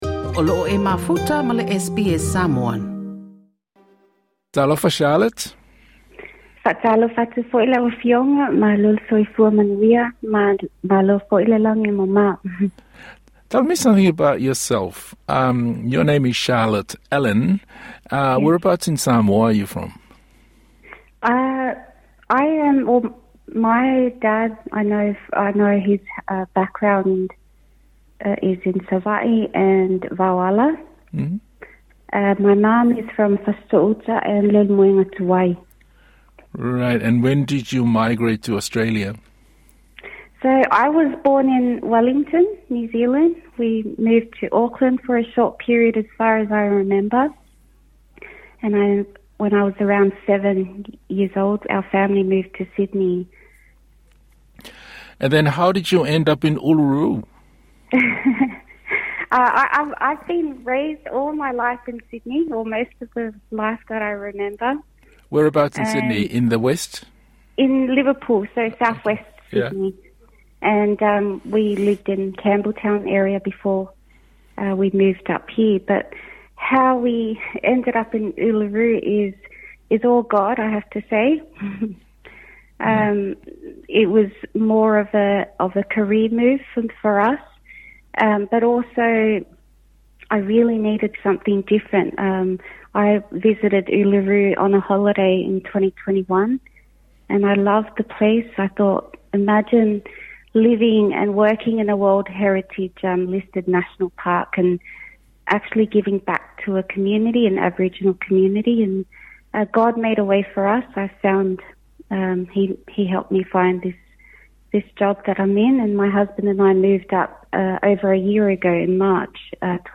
I le talanoaga lenei ma le SBS Samoan